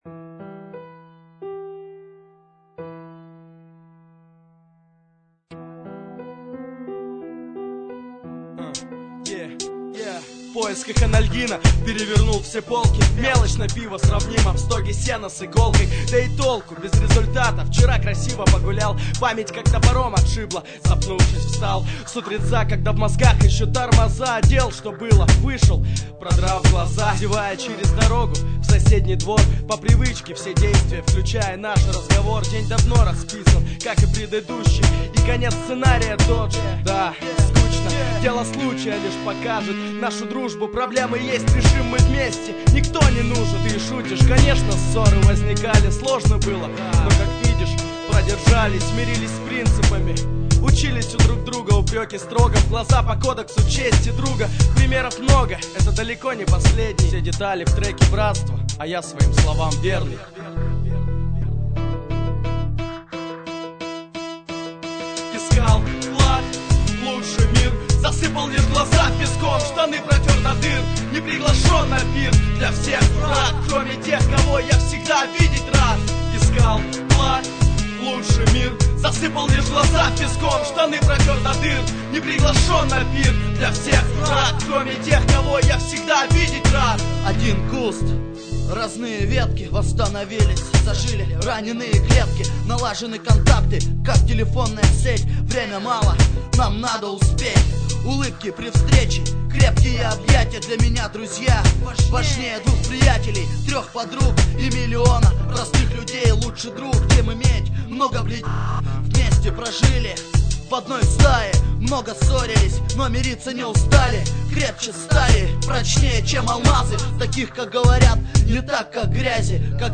mp3,1861k] Рэп